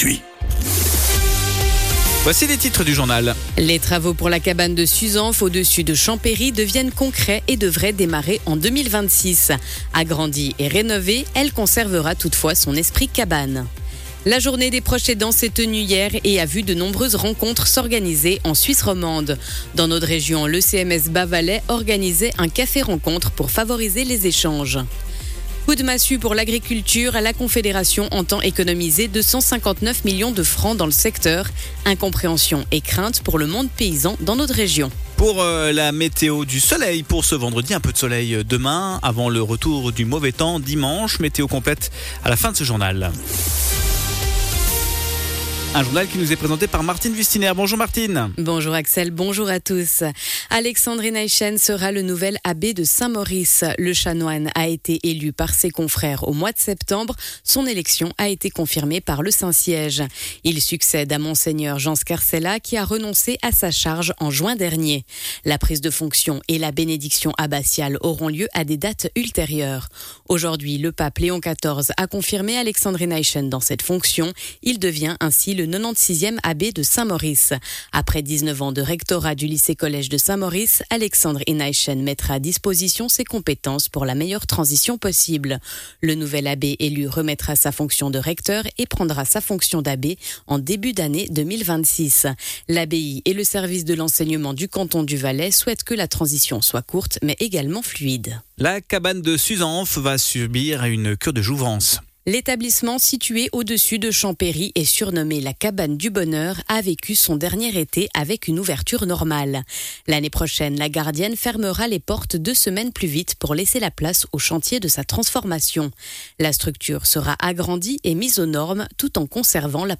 Le journal de midi du 31.10.2025